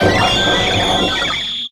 Grito de Arceus.ogg
Grito_de_Arceus.ogg.mp3